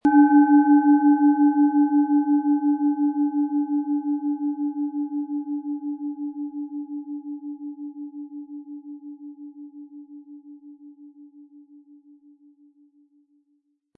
Diese von Hand getriebene Planetentonschale Mars wurde in einem kleinen indischen Dorf gefertigt.
Durch die überlieferte Herstellung hat diese Schale vielmehr diesen besonderen Ton und die innere Berührung der liebevollen Handfertigung.
Den passenden Klöppel erhalten Sie umsonst mitgeliefert, er lässt die Schale voll und wohltuend klingen.
MaterialBronze